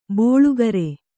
♪ bōḷugare